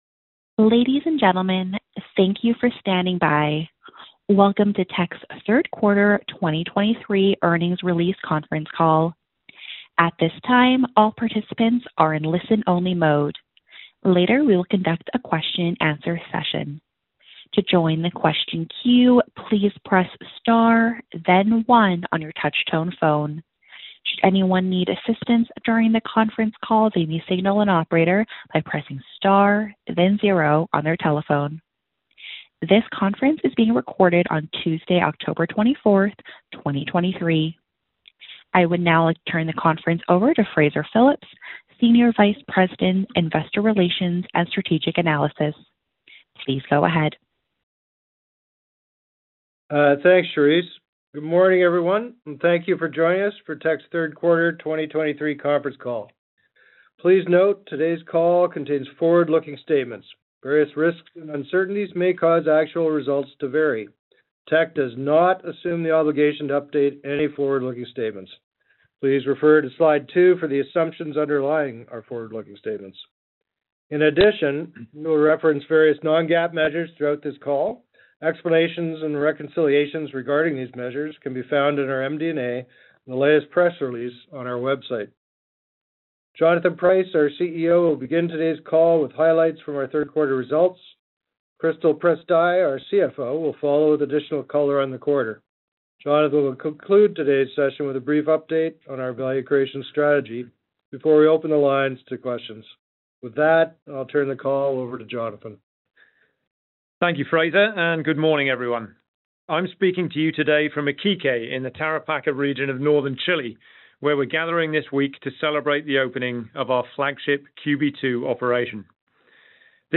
Q3 2023 Financial Report [PDF - 0.46 MB] Q3 2023 Financial Report Conference Call Slides [PDF - 3.17 MB] Q3 2023 Financial Report Conference Call Audio [MP3 - 49.62 MB] Q3 2023 Financial Report Conference Call Transcript [PDF - 0.25 MB]